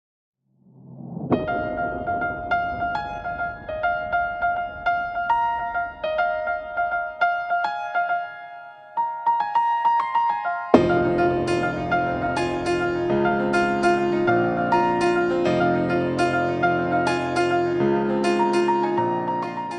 Download free high-quality instrumental MP3 ringtone.